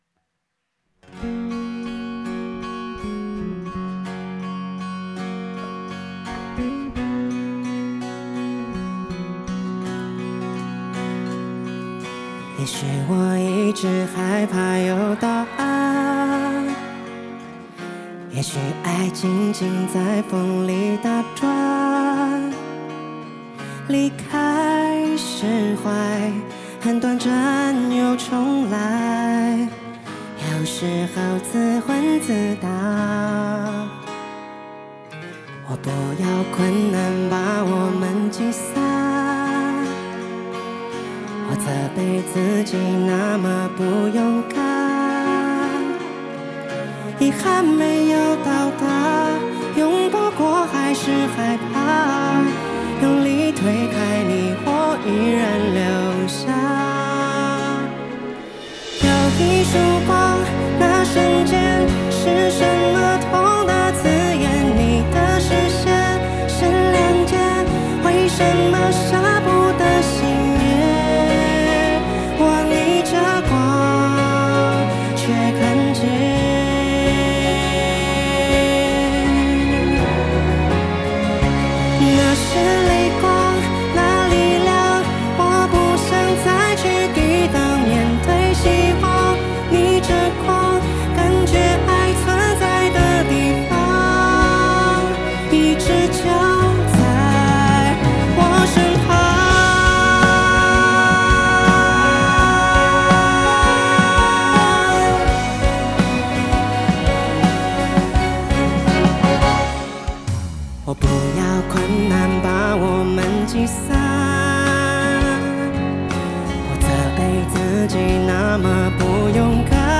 Ps：在线试听为压缩音质节选，体验无损音质请下载完整版
Live